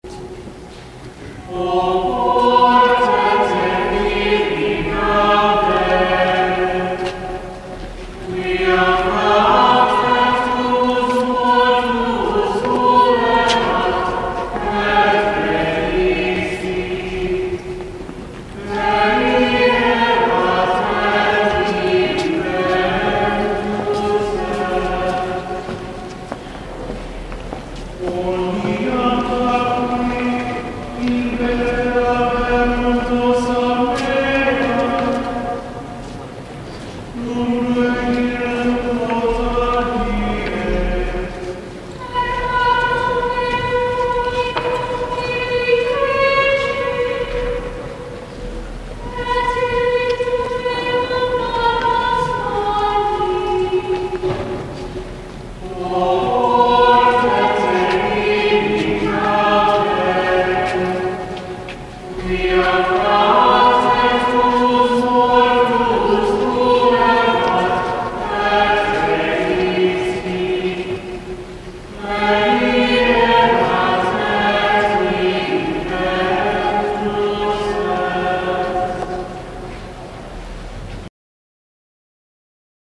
From the Fourth Sunday of Lent at Saint John’s Abbey:
(You’ll hear the Psallite congregational antiphon blurring into the end of the Latin introit)